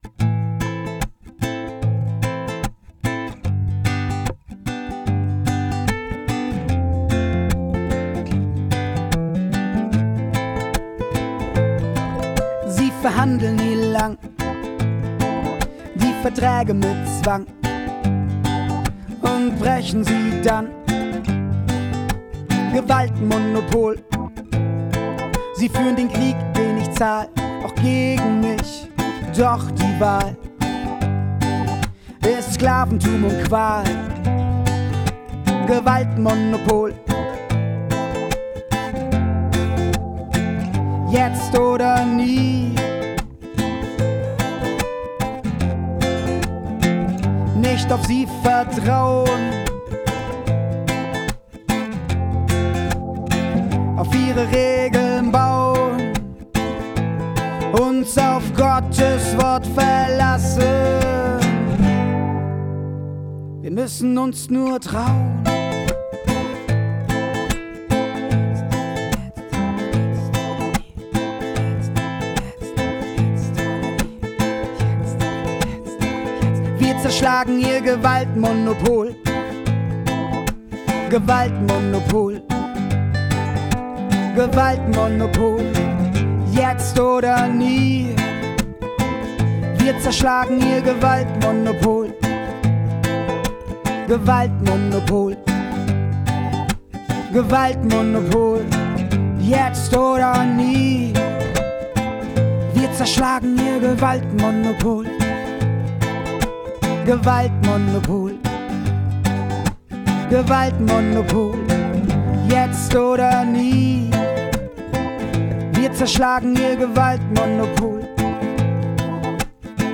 Gesang
Gitarre
Keyboard